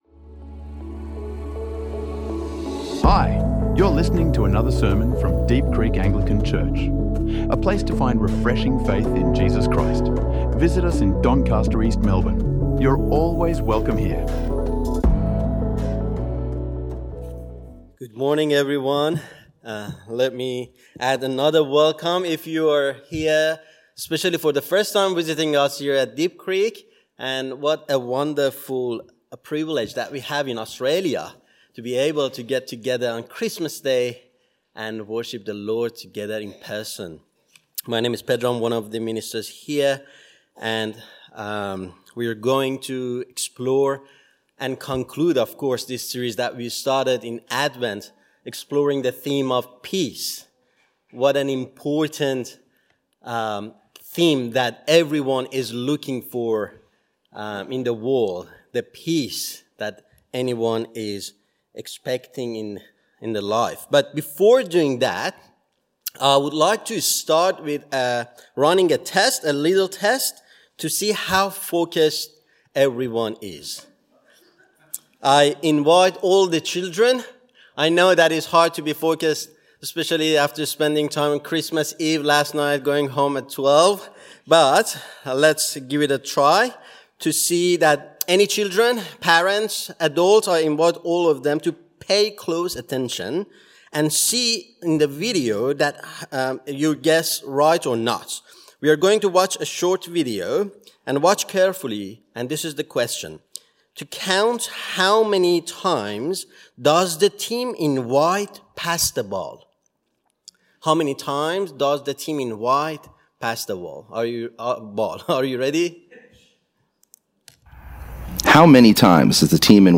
A Peace for All the Earth | Sermons | Deep Creek Anglican Church